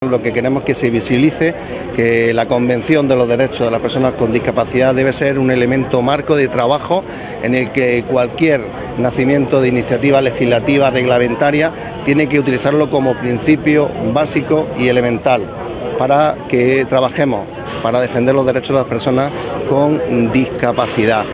El acto se ha desarrollado en la Avenida Federico García Lorca de la capital y ha contado con el conjunto de entidades de Almería que representan a las personas con discapacidad.
TOTAL-ANGEL-ESCOBAR-Vicepresidente-Diputacion.wav